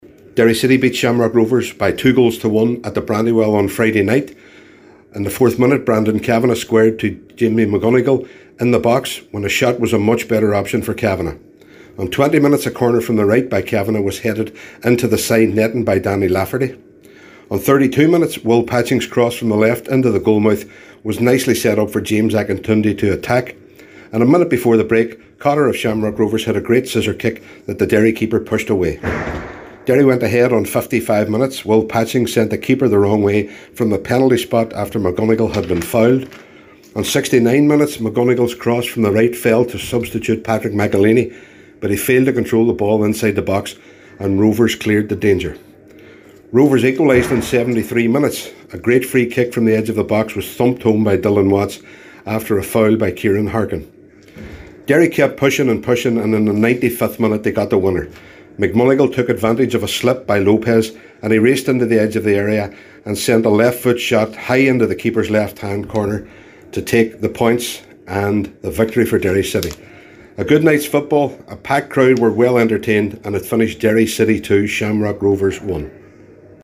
FT Report: Derry City 2 Shamrock Rvs 1